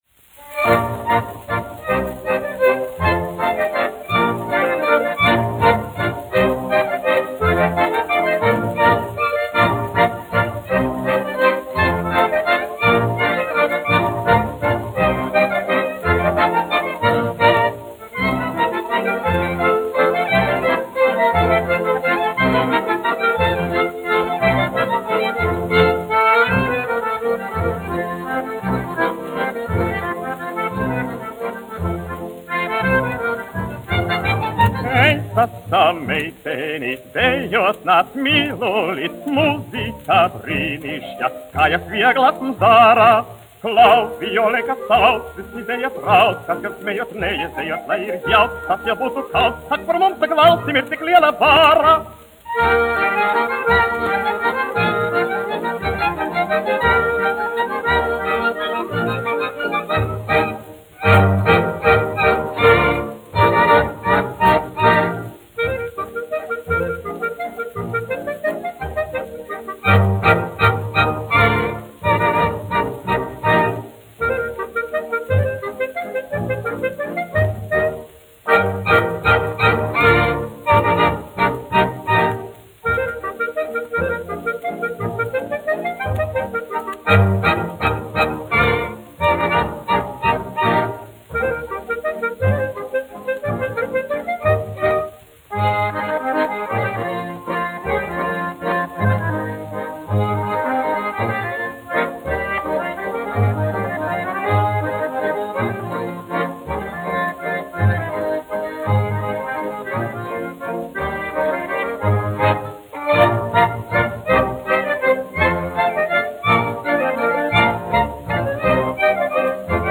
1 skpl. : analogs, 78 apgr/min, mono ; 25 cm
Populārā mūzika -- Latvija
Skaņuplate